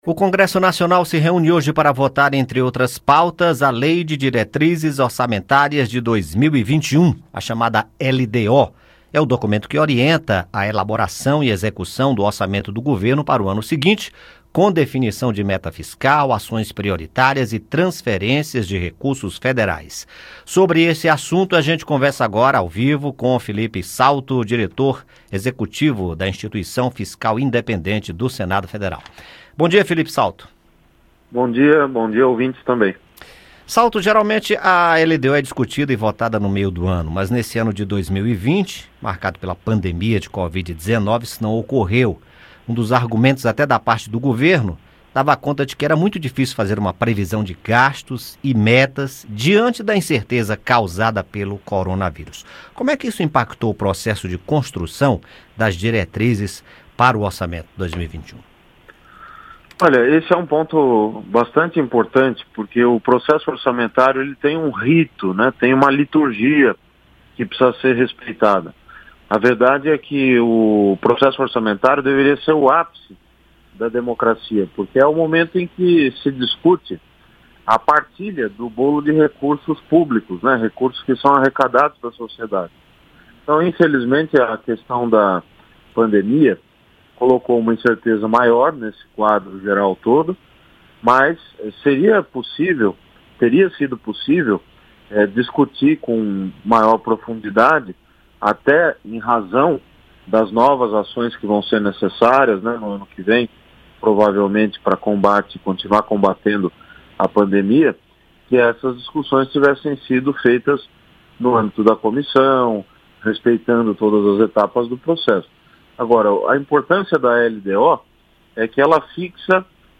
Entrevista: Congresso vota LDO nesta quarta-feira